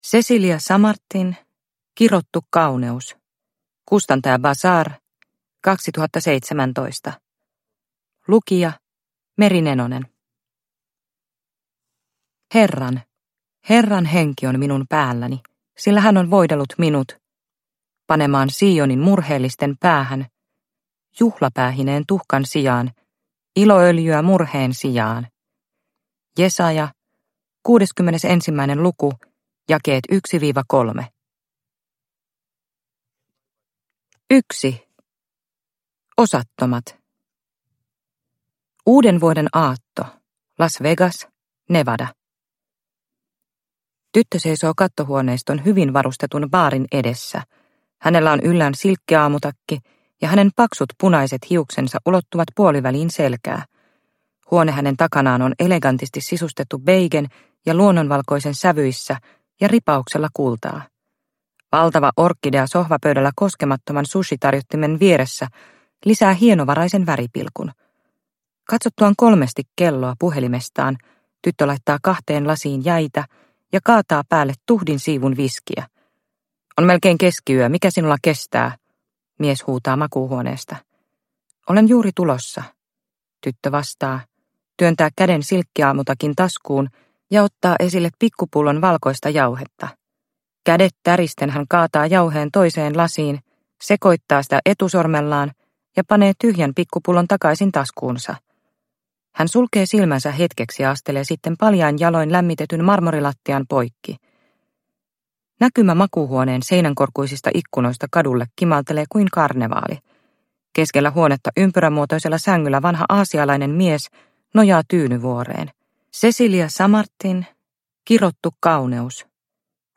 Kirottu kauneus – Ljudbok – Laddas ner